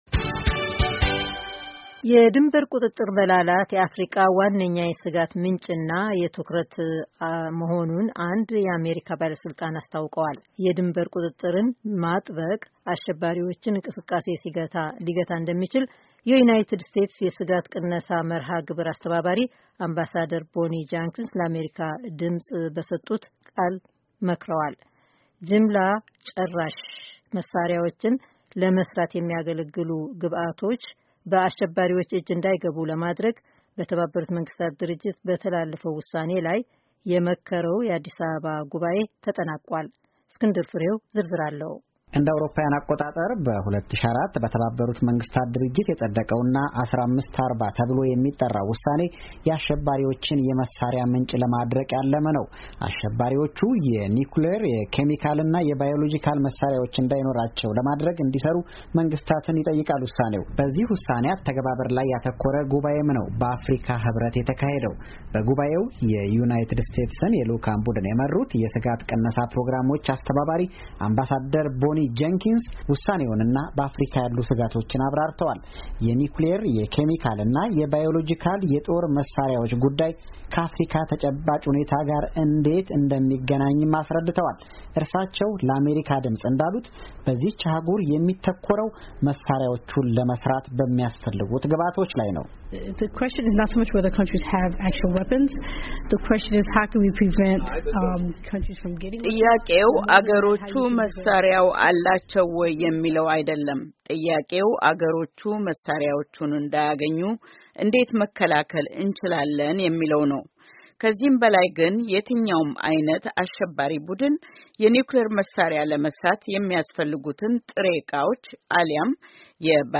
የድንበር ቁጥጥርን ማጥበቅ አአሸባሪዎችን እንቅስቃሴ ሊገታ እንደሚች የዩናይትድ ስቴትስ የሥጋት ቅነሳ መርኃግብሮች አስተባባሪ አምባሳደር ቦኒ ጀንኪንስ ለአሜሪካ ድምፅ ድምፅ በሰጡት ቃል መክረዋል።